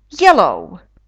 yellow [jeləu]